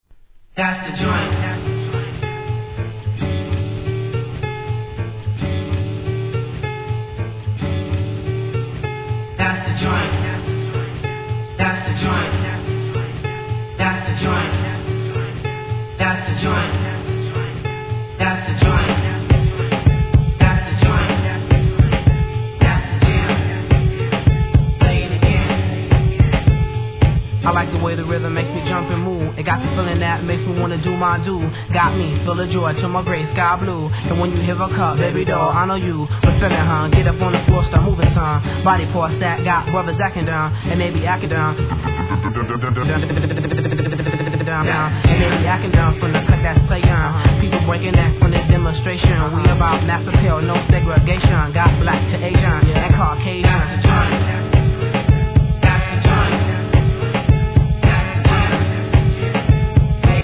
HIP HOP/R&B
使いの大人気REMIX!!